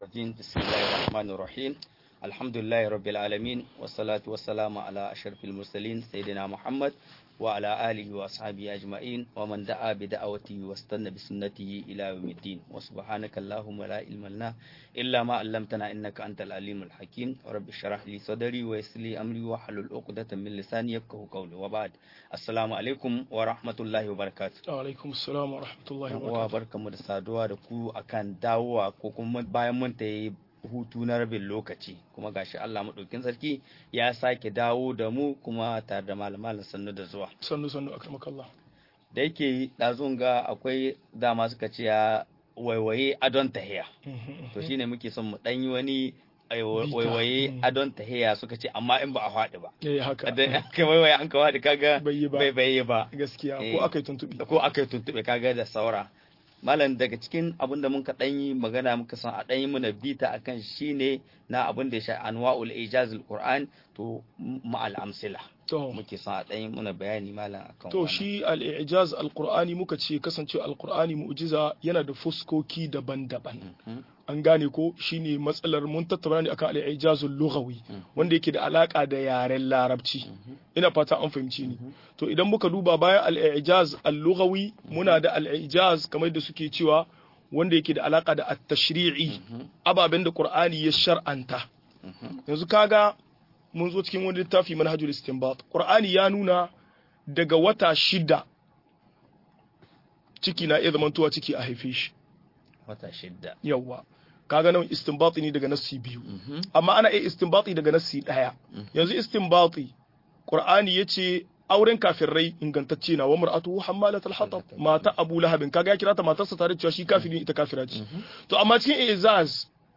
Ilimin munasaba - MUHADARA